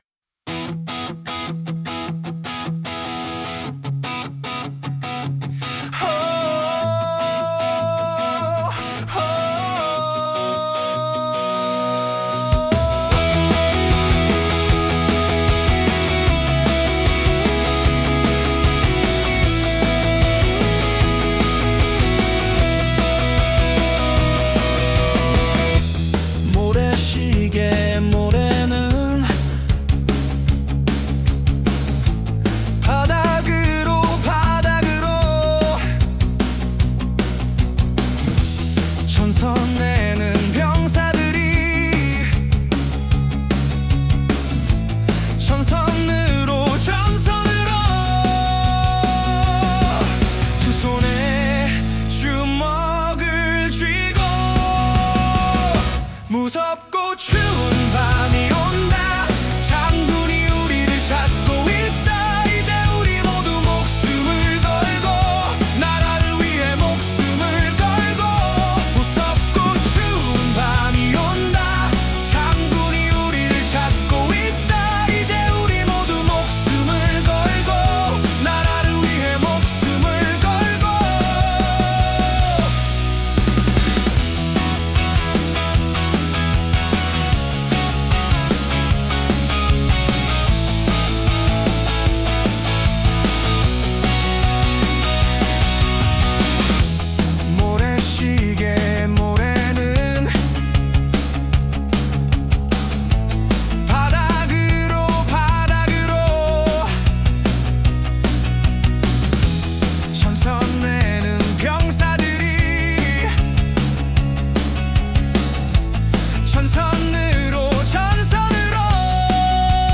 출근길 팝음악 - 모래시계